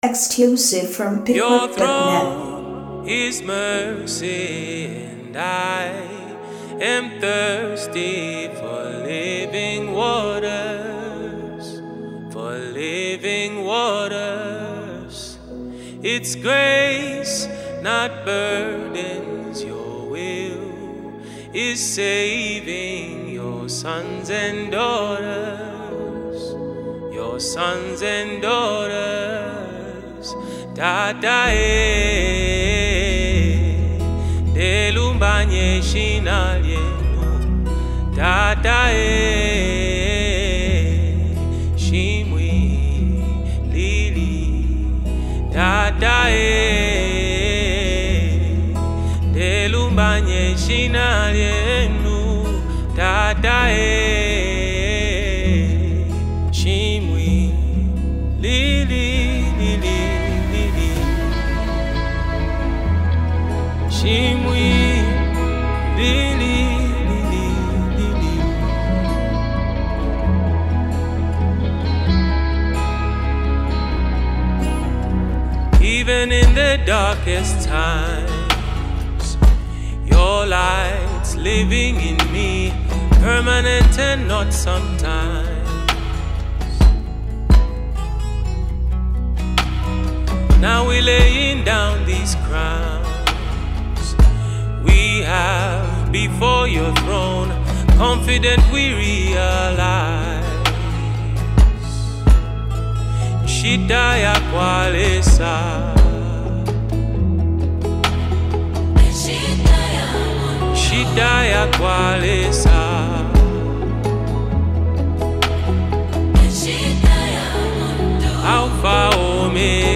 a powerful Afrogospel worship song